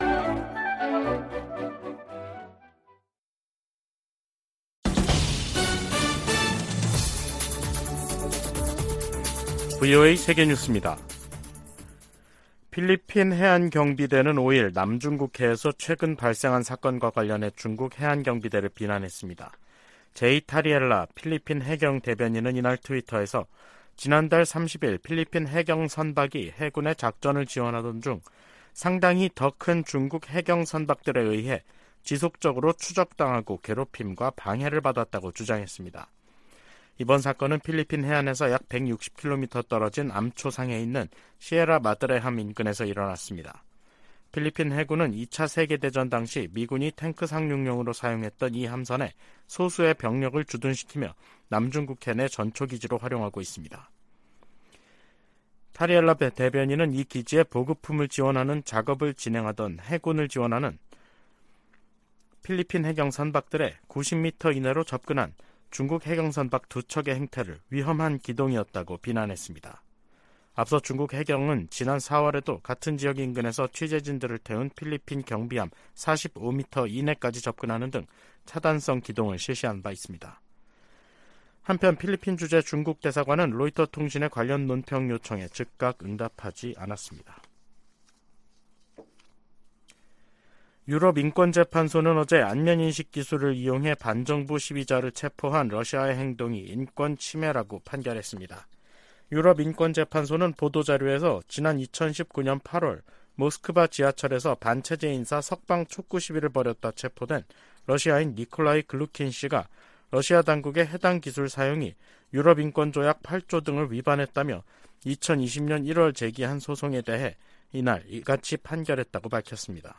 VOA 한국어 간판 뉴스 프로그램 '뉴스 투데이', 2023년 7월 5일 3부 방송입니다. 한국과 중국이 시진핑 국가주석 3연임 확정 후 첫 고위급 공식 회담을 가졌습니다. 북한과 일본이 정상회담을 염두에 두고 고위급 접촉을 모색하고 있습니다. 북대서양조약기구(NATO·나토) 정상회의를 앞두고 미국과 한국의 나토 대사가 만나 협력 방안을 논의했습니다.